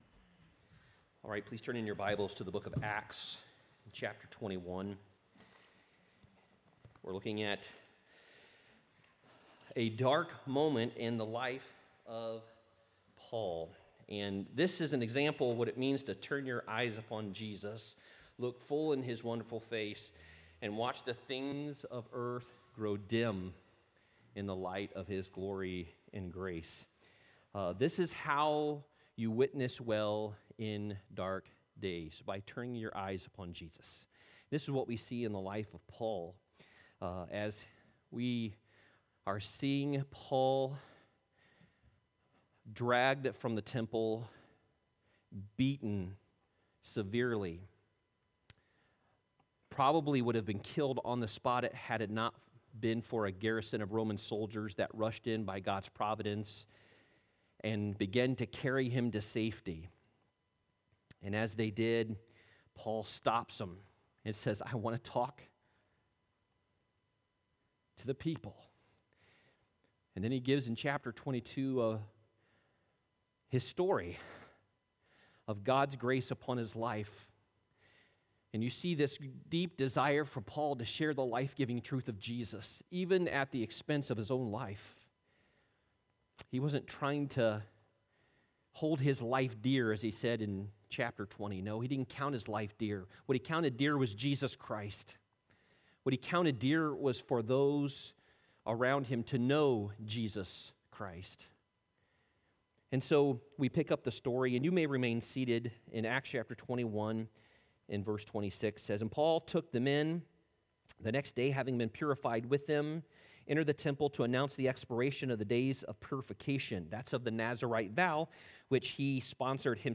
Worship Service 08/13/2023